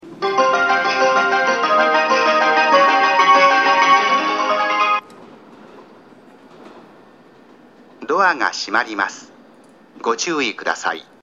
スピーカ ーはRoland型が設置されており音質は若干悪い感じがします。
音量に関してはやや小さめですので密着収録をお勧めします。特に最後の自動放送が非常に小さいです。
１番線JN：南武線
発車メロディー余韻切りです。